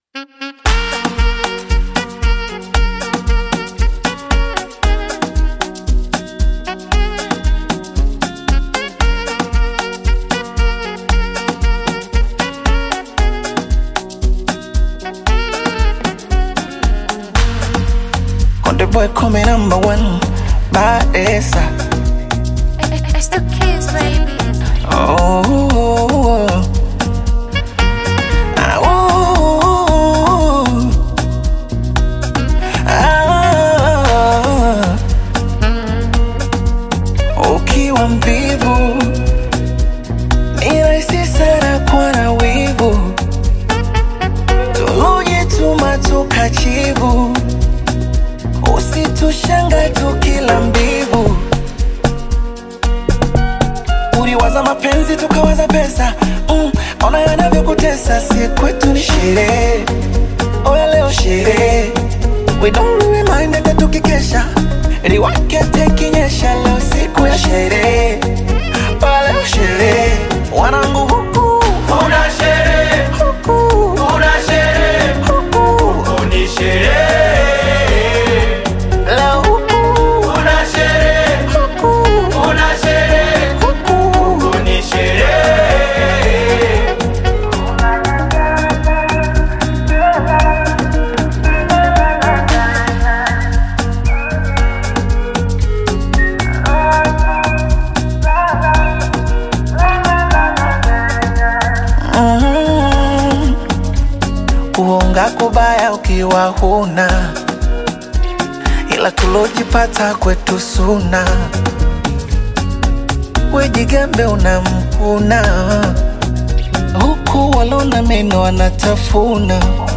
Bongo Flava
Tanzanian Bongo Flava artist and singer